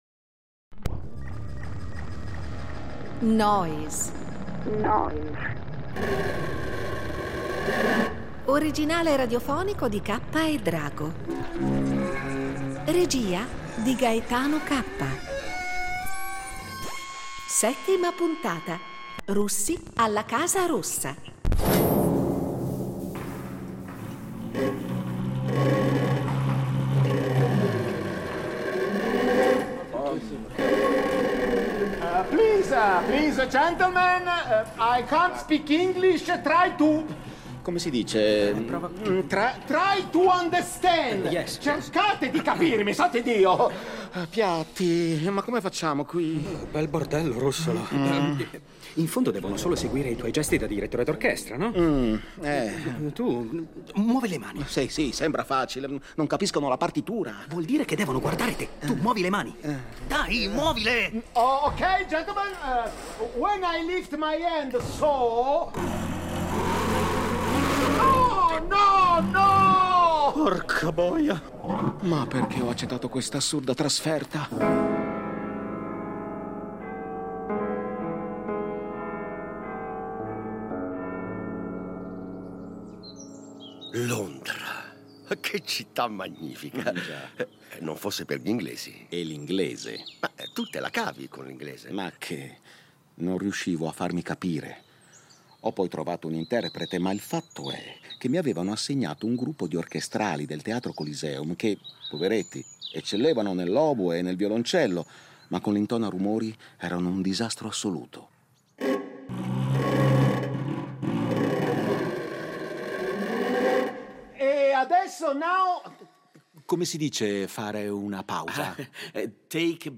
Oggi i libri di storia dell’arte si occupano di Russolo soprattutto in quanto firmatario, nel 1910, con Carrà e Boccioni, del primo manifesto della pittura futurista. Nel radiodramma abbiamo però privilegiato il Russolo musicista, compositore e inventore di strumenti sonori.